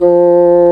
Index of /90_sSampleCDs/Roland L-CDX-03 Disk 1/WND_Bassoons/WND_Bassoon 4
WND BSSN F3.wav